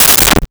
Umbrella Opened 03
Umbrella Opened 03.wav